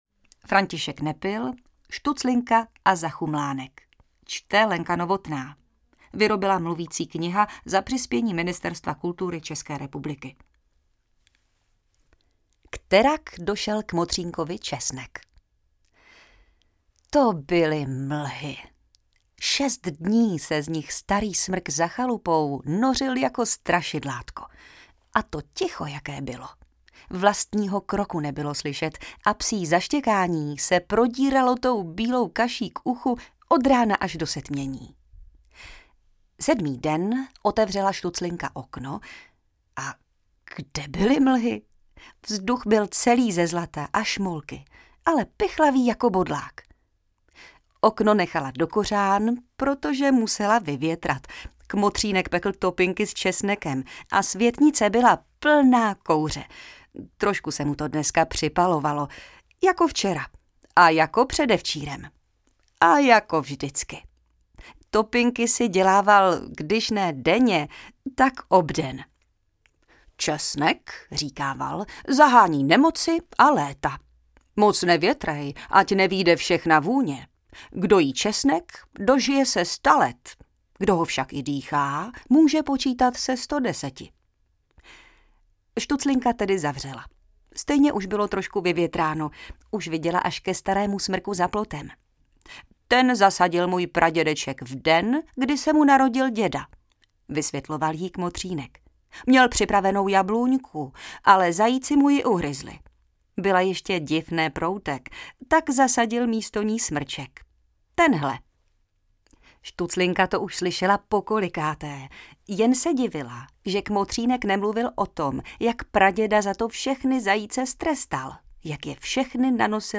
Mluvící kniha z.s.